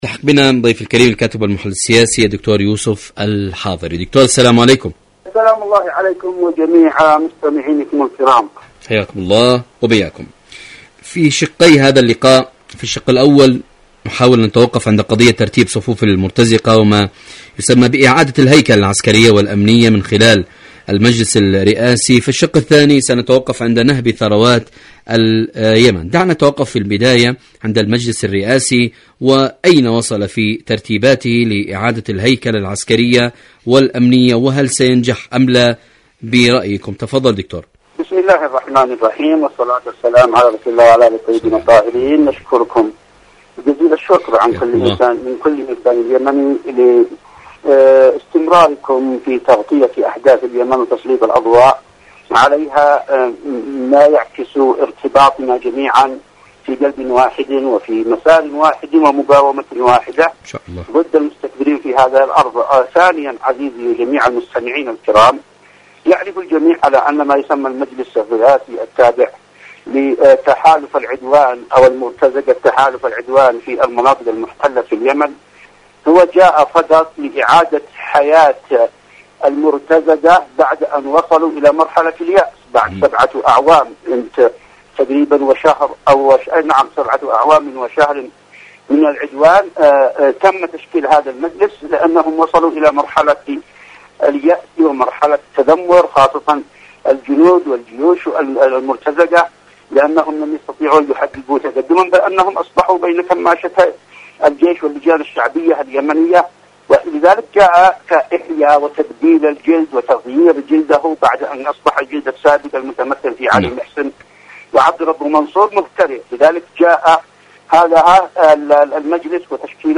مقابلات إذاعية